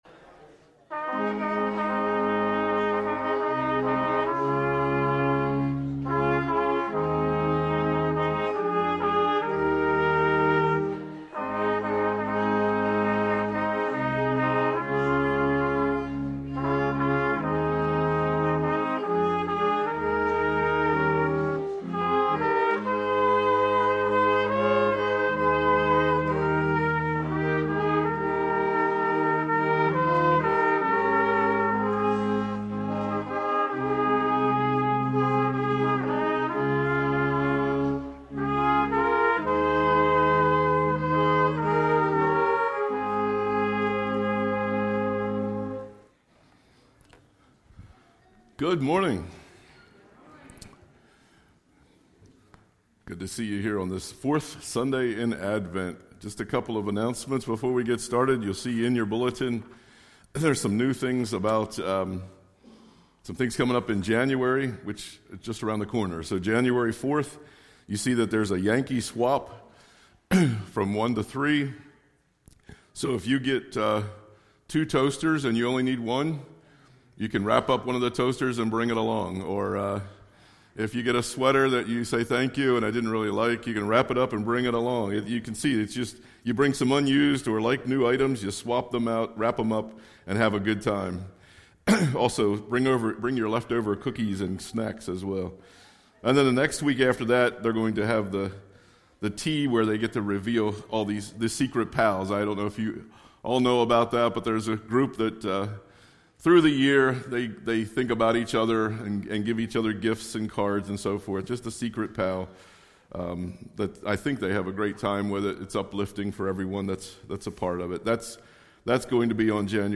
1 Sunday Worship December 22, 2024 1:11:16